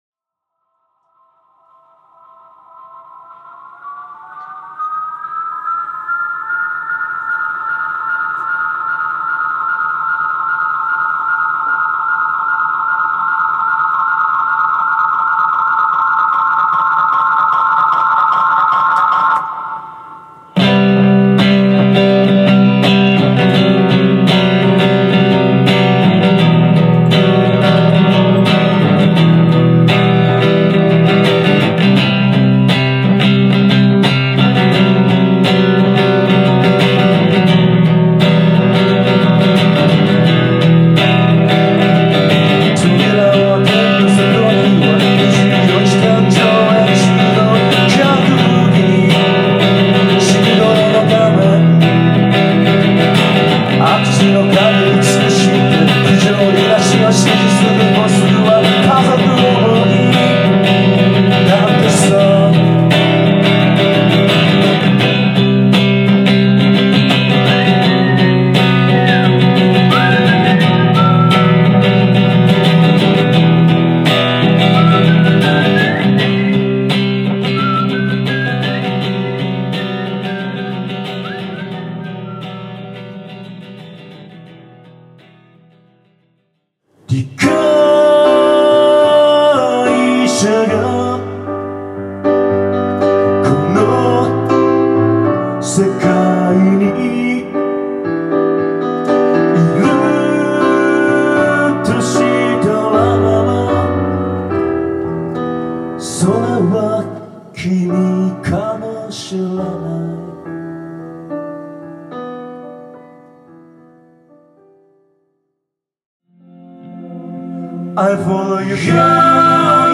下北沢ラウン
弾き語りワンマン
ひとりぼっちのメロディ <※E.guitar>